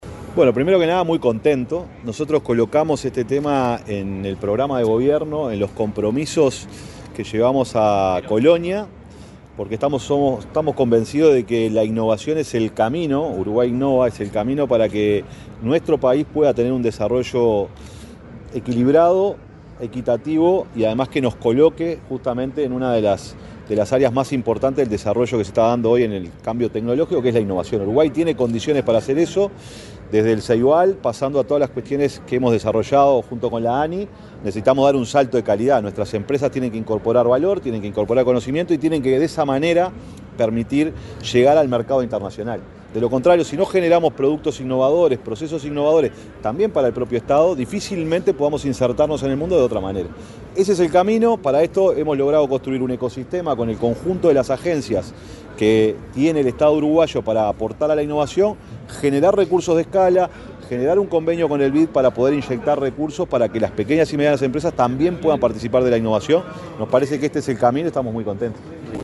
Declaraciones del secretario de Presidencia, Alejandro Sánchez
El secretario de la Presidencia, Alejandro Sánchez, dialogó con la prensa antes de participar en el lanzamiento del programa Uruguay Innova.